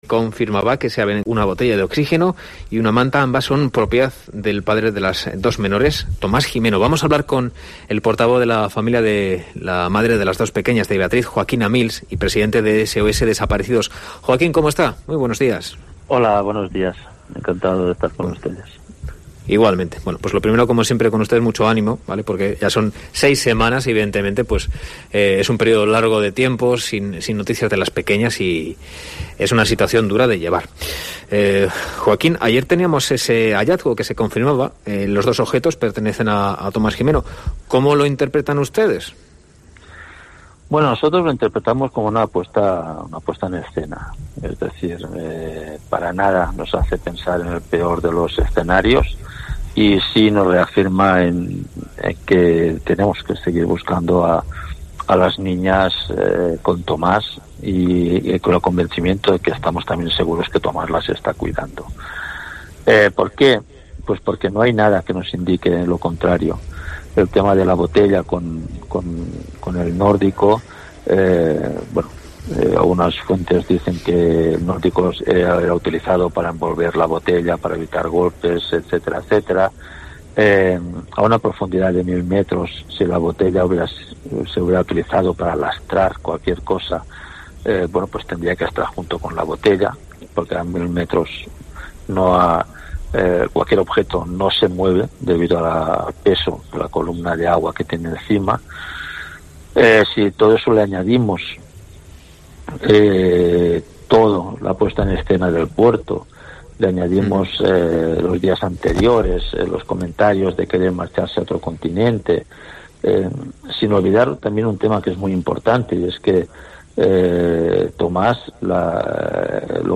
En una entrevista en COPE Canarias